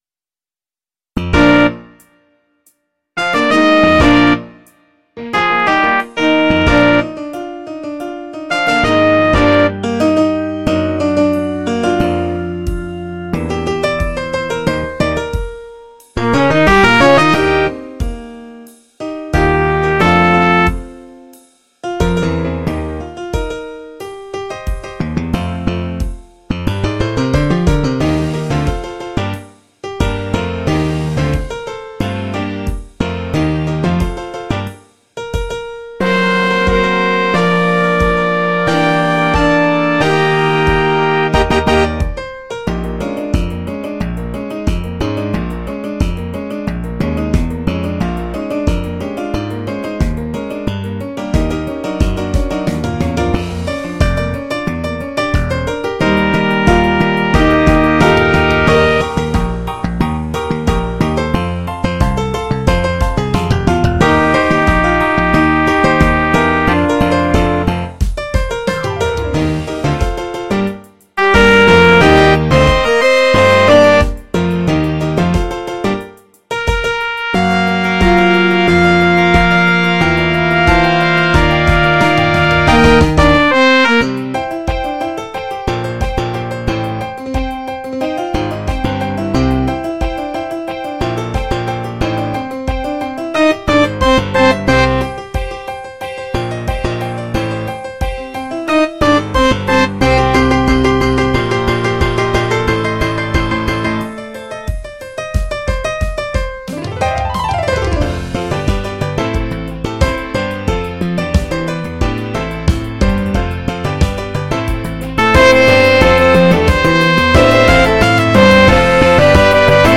SSA
Opener